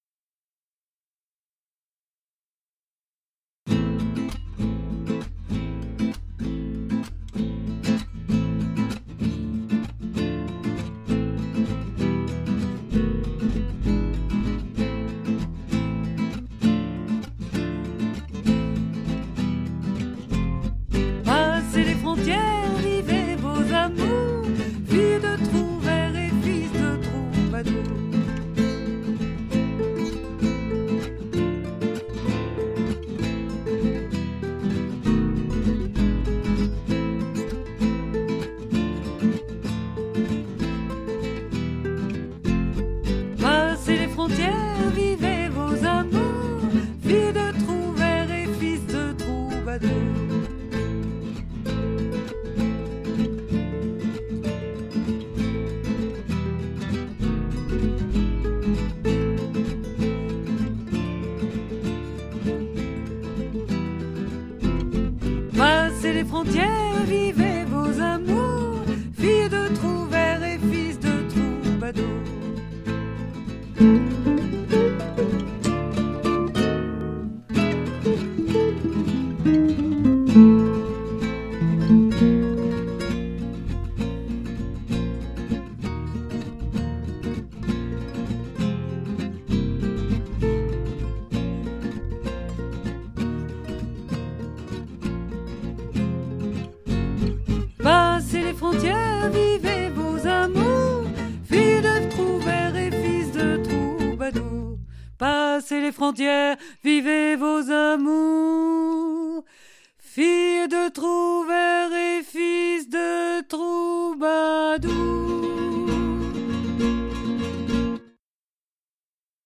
ORCHESTRA+VOIX2.mp3